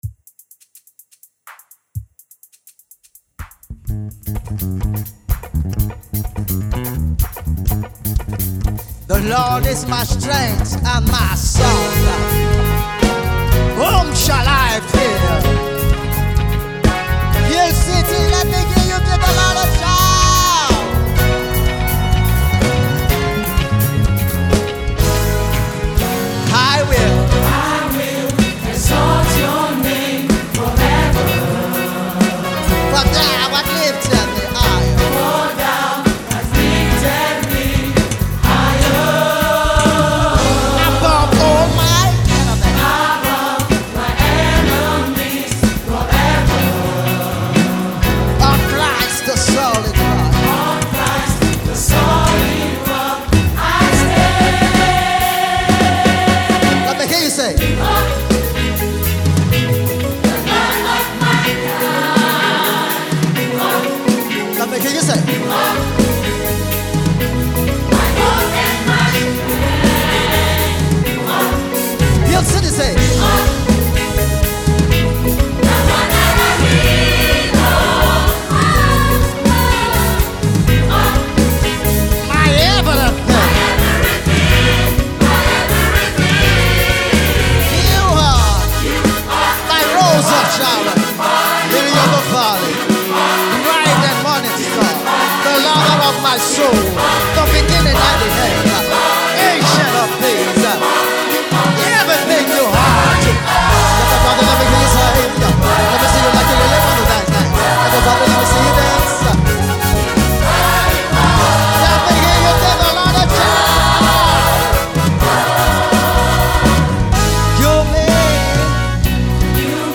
rapper
You can’t hear the song and not dance to it.